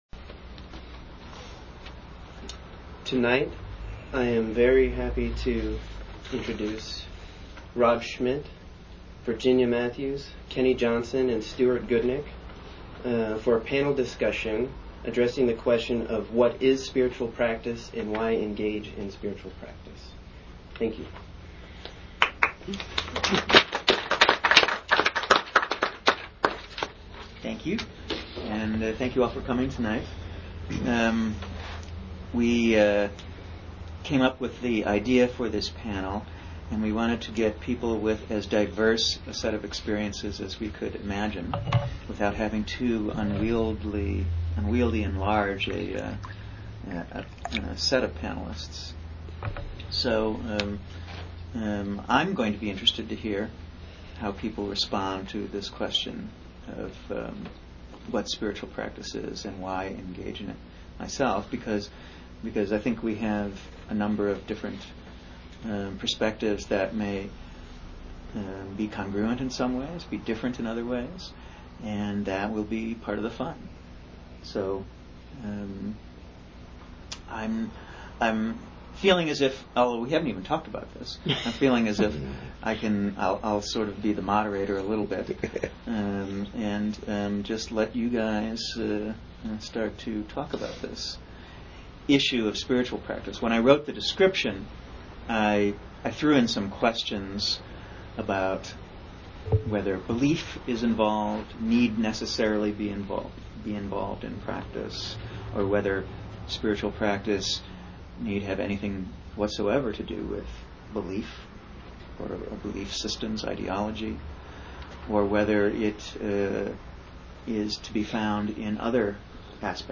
Archive of an event at Sonoma County's largest spiritual bookstore and premium loose leaf tea shop.
What Is Spiritual Practice? Why Engage in Spiritual Practice? Panel Discussion with diverse Practitioners
In this panel discussion, practitioners from a variety of traditions engage with the question of the nature of spiritual practice. For example, is belief in itself practice, or is belief different than practice?